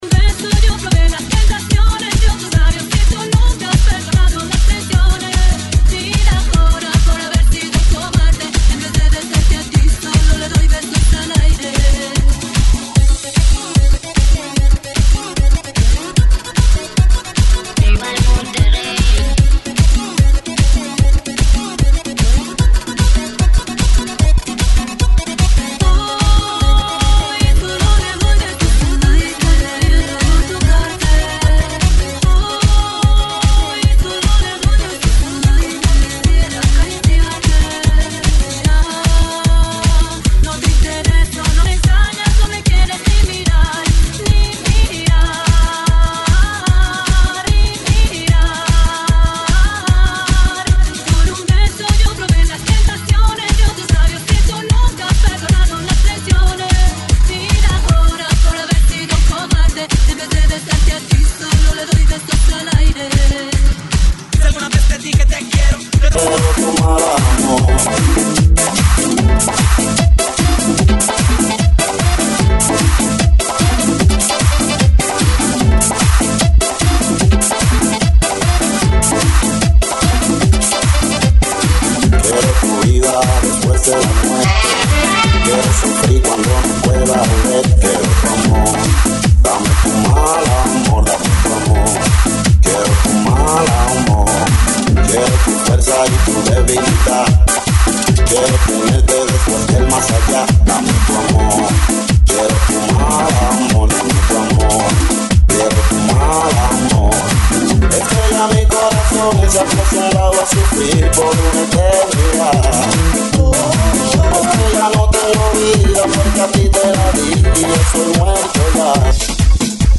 GENERO: LATINO – TRIBAL
LATINO, TRIBAL,